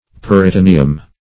Peritonaeum \Per`i*to*n[ae]"um\, n. (Anat.)